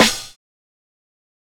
TC SNARE 23.wav